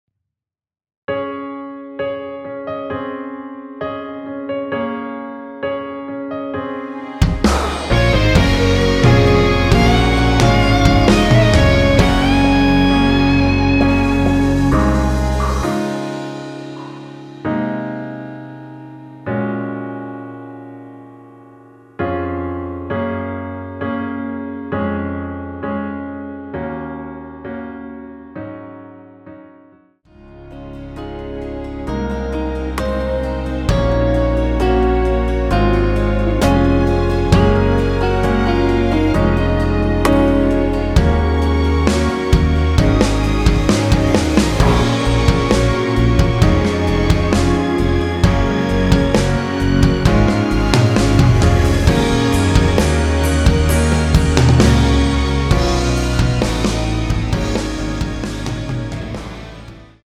원키(1절앞+후렴)으로 진행되는 MR입니다.
Db
앞부분30초, 뒷부분30초씩 편집해서 올려 드리고 있습니다.
중간에 음이 끈어지고 다시 나오는 이유는